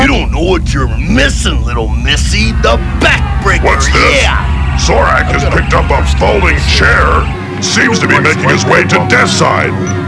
(Crowd noise grows in background, while Granpa Ghostal goes on talking)